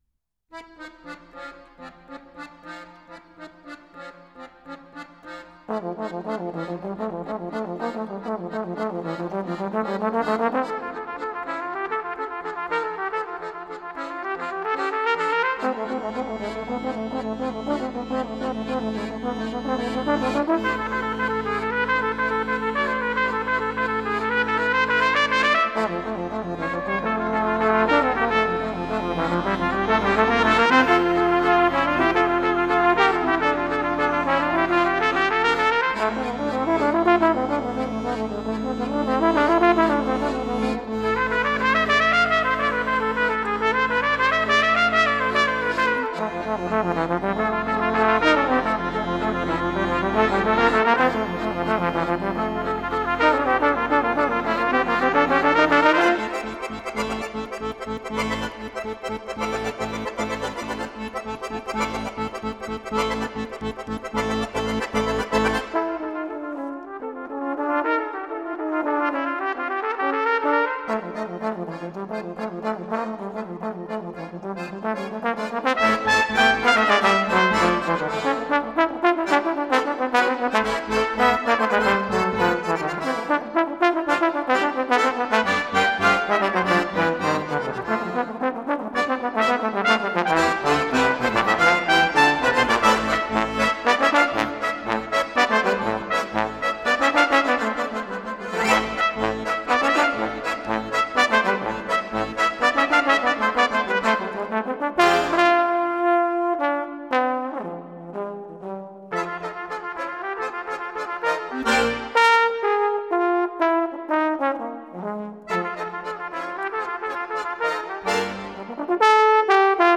With bass trumpet.